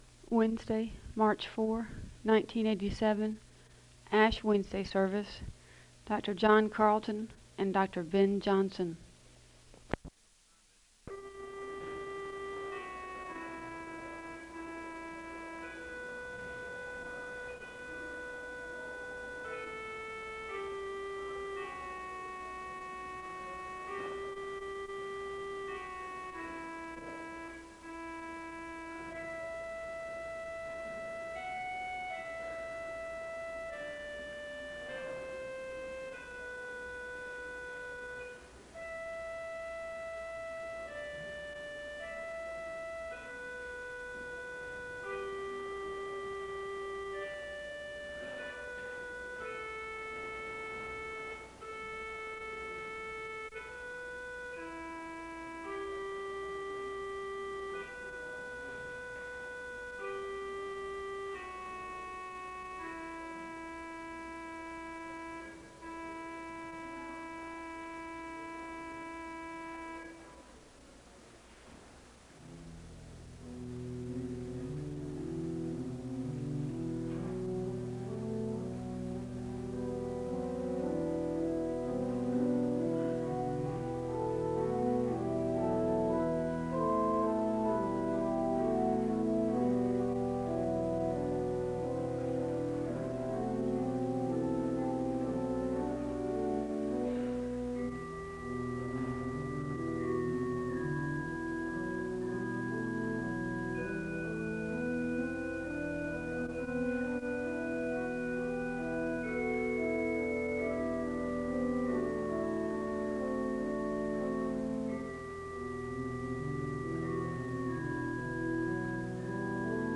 The service begins with organ music (0:00-4:36). There is a blessing read (4:37-5:02).
There is a responsive reading (7:39-9:02). There is a moment of silence, then the ushers come forward to receive the papers to be burned (9:03-12:44).
There is organ music (23:48-27:19). The choir sings a song of worship (27:20-31:13).